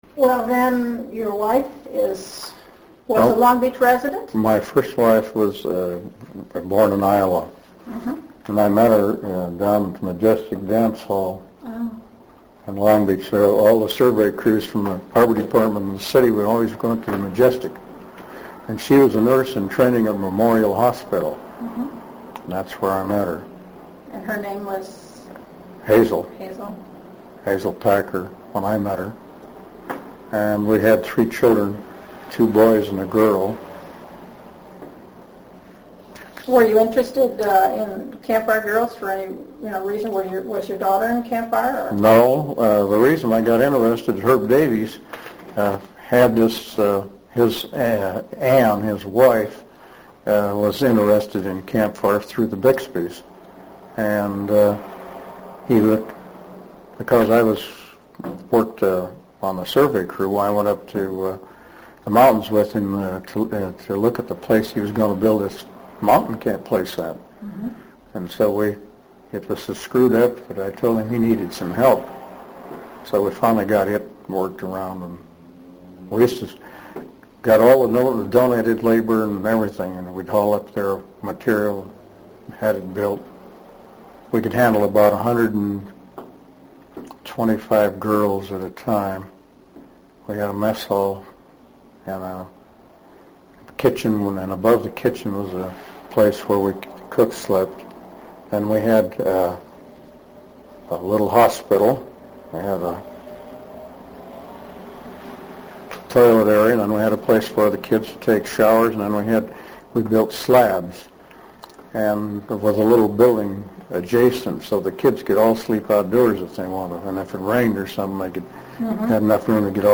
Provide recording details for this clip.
This actual interview was 30 minutes longer; after the first 30 minutes, the recorder failed, but began again to record the last 15 minutes. The interview was part of a project to study the impact of the discovery of oil on the development of Long Beach.